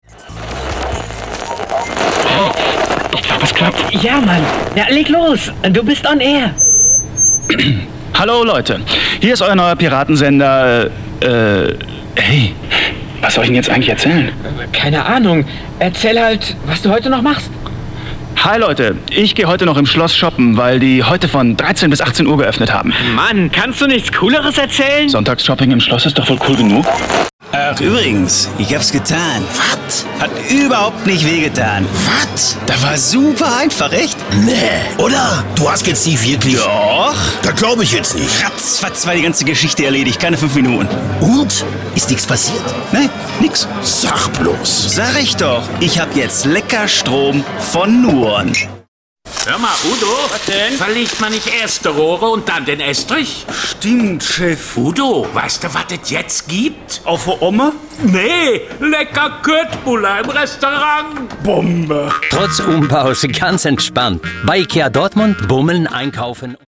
Sprecher deutsch. Frische, klare, direkte Stimme.
Sprechprobe: Sonstiges (Muttersprache):
voice over artist german